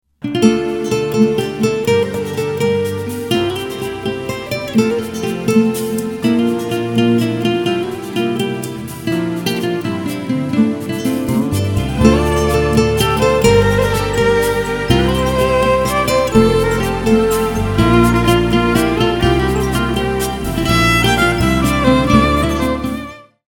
رینگتون احساسی و بیکلام